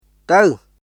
[タウ　tə̀w]